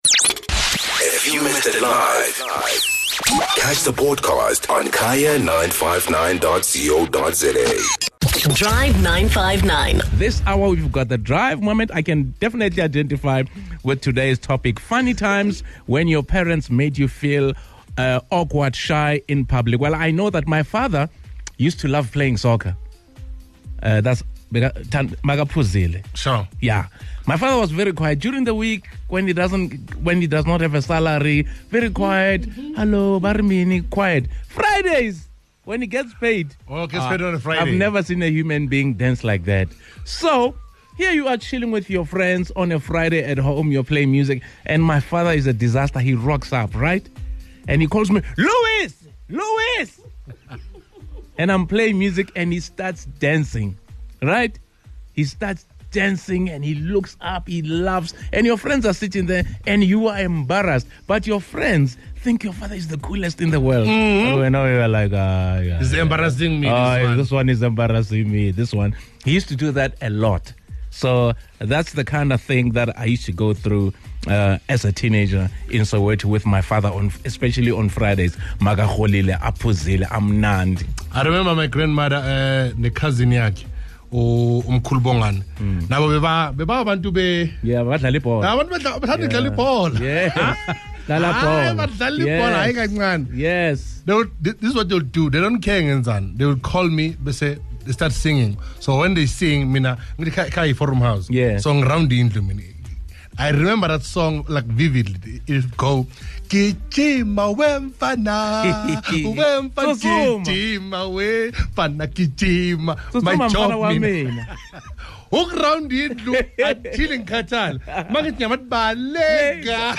Whether it was at family gatherings, school moments, in the streets, or anywhere, parents sometimes do things that make us feel super awkward in public! Listeners and the Drive 959 Team share their funny stories!